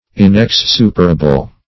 Search Result for " inexsuperable" : The Collaborative International Dictionary of English v.0.48: Inexsuperable \In`ex*su"per*a*ble\, a. [L. inexsuperabilis; pref. in- not + exsuperabilis that may be surmounted.